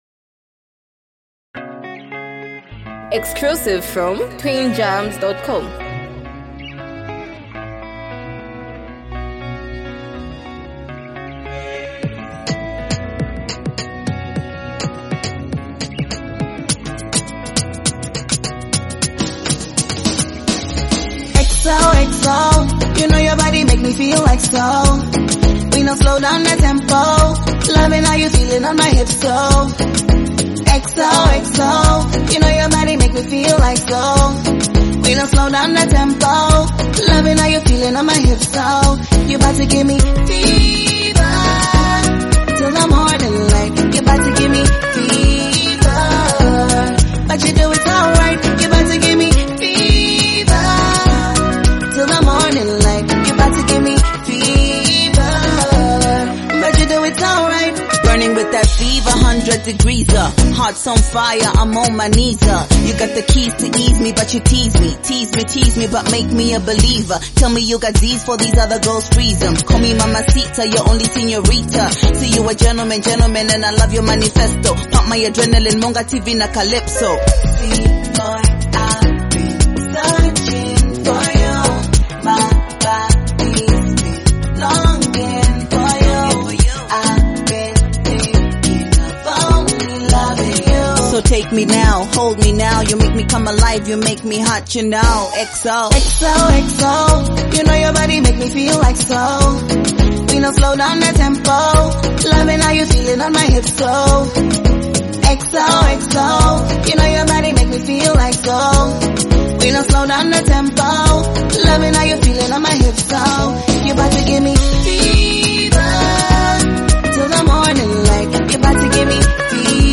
smooth, emotionally driven song
soulful portrayal of romance